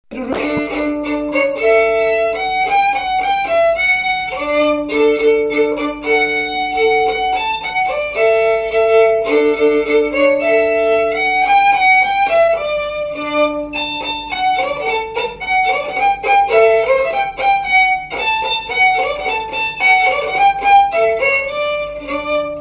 Résumé instrumental
gestuel : danse
Pièce musicale inédite